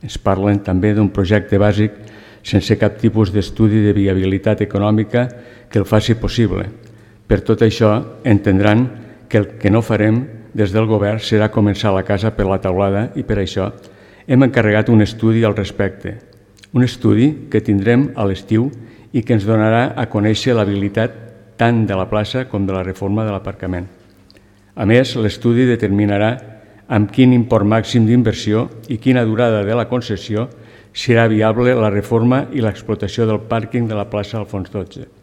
Escoltem a Mar Lleixà, portaveu d’ERC i a Francesc Vallespí regidor de Movem-PSC